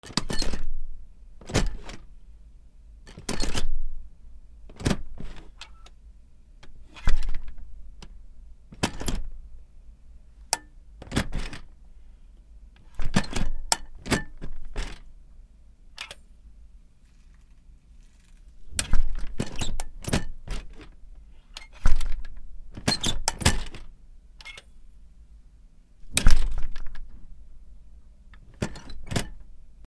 扉
/ K｜フォーリー(開閉) / K05 ｜ドア(扉)
『カチャカチャ』